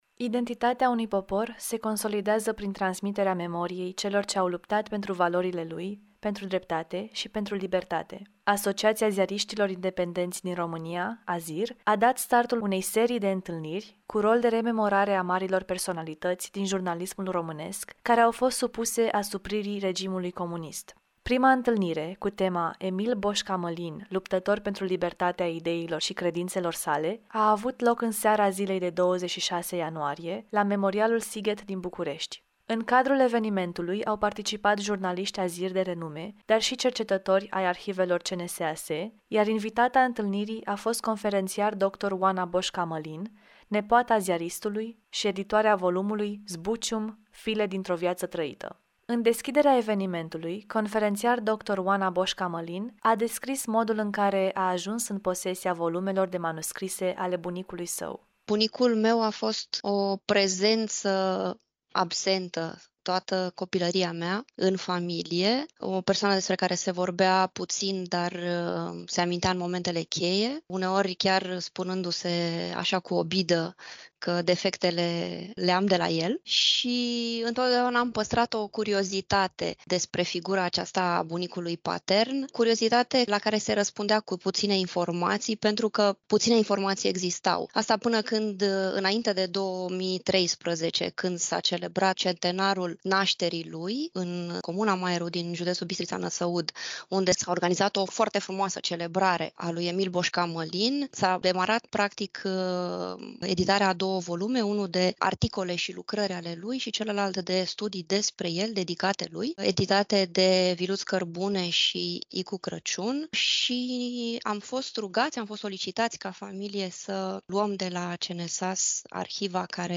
in emisiunea „Viata Cetatii” din 27.01.2024